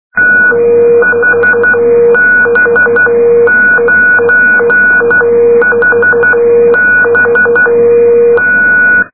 Les fichiers à télécharger sont compressés au format MP3 à 1ko/sec, ce qui explique la très médiocre qualité du son.
bande 2 m, balise HB9HB, FSK, les traits-points sont plus aigus que les espaces